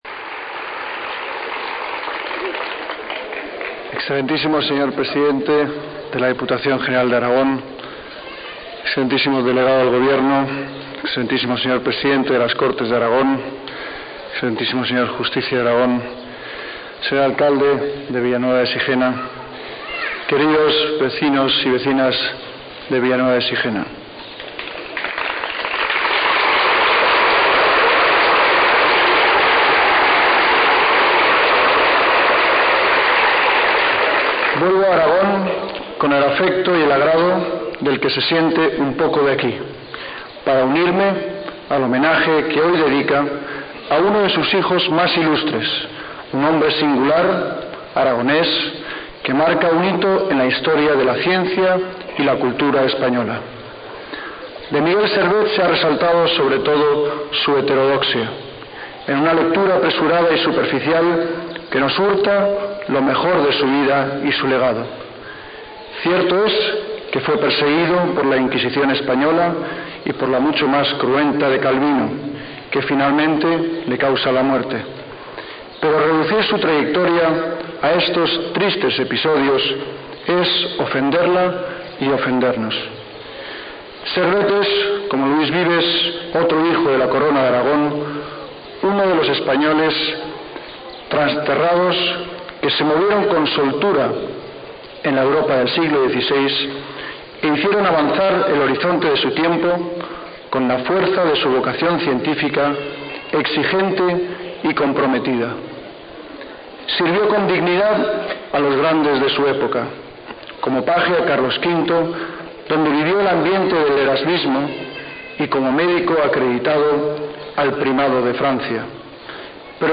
Discurso del Príncipe Felipe en Villanueva de Sijena | RdF
El Príncipe Felipe dirigiéndose a los vecinos de Villanueva de Sijena
Con motivo de la inauguración de la Casa Museo de Miguel Servet
sprincipefelipe_discurso.mp3